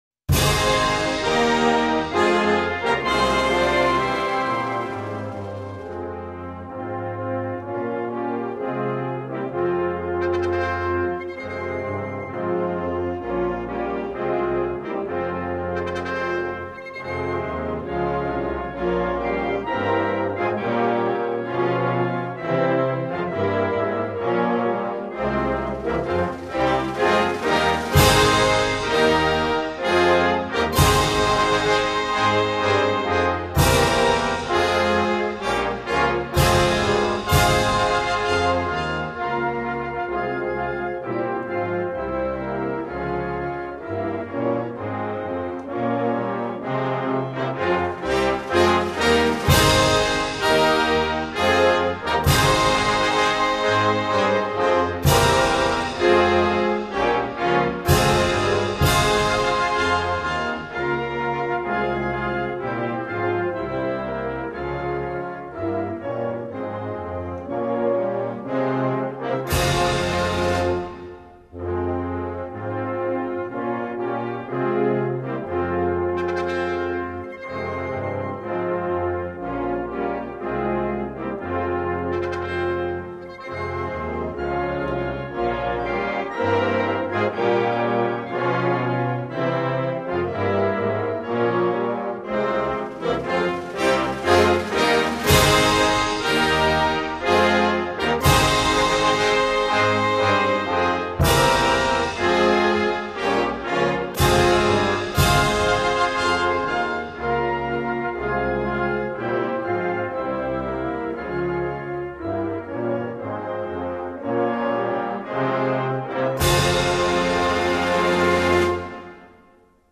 торжественную мелодию
инструментальный